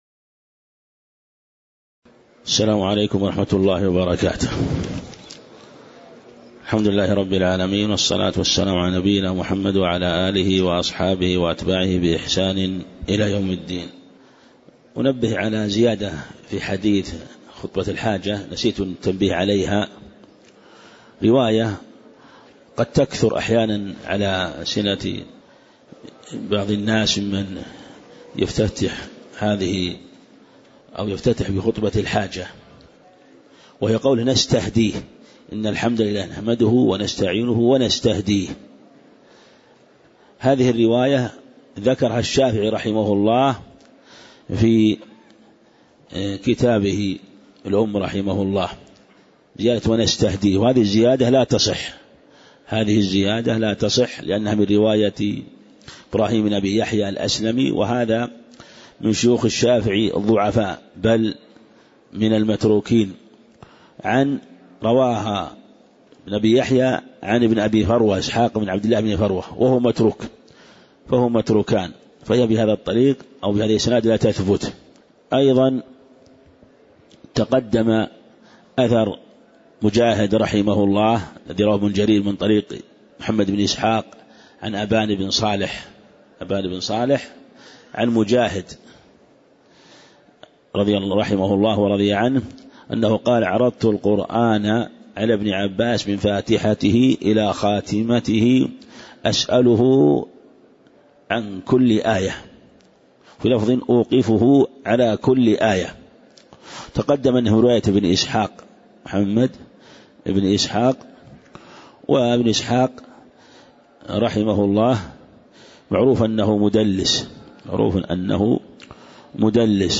تاريخ النشر ١٥ شوال ١٤٣٨ هـ المكان: المسجد النبوي الشيخ